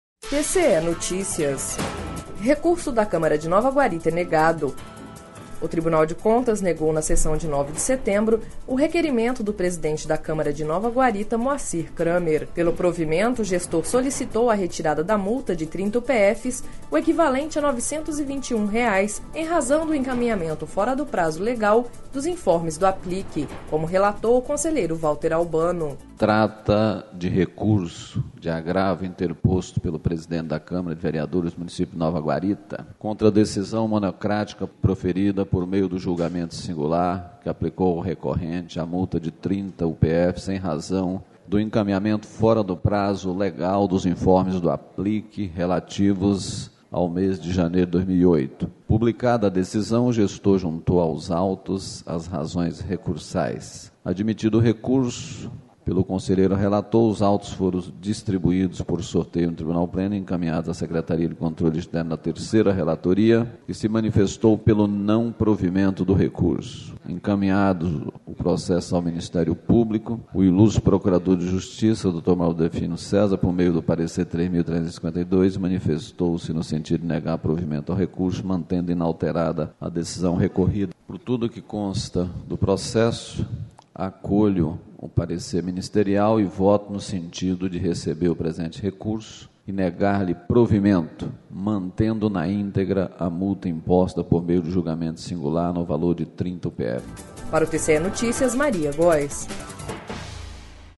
Sonora: Valter Albano - conselheiro presidente do TCE-MT